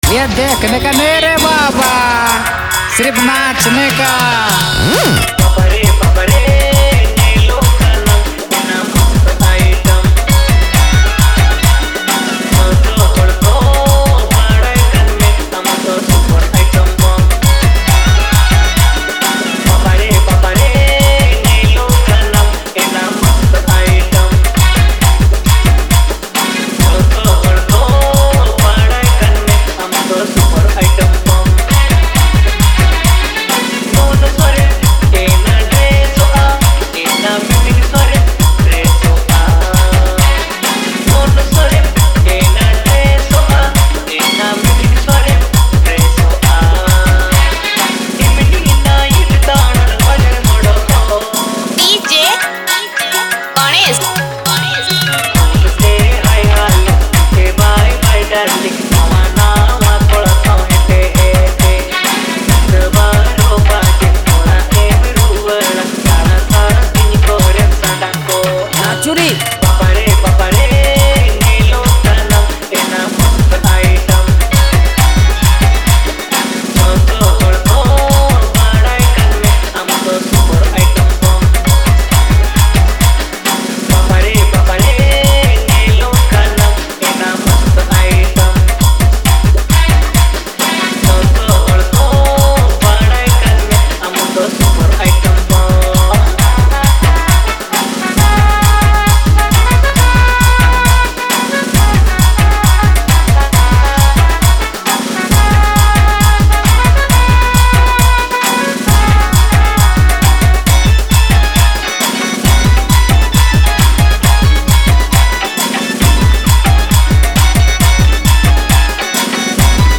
Santali Dabung